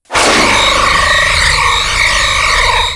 heavy tractor beam.wav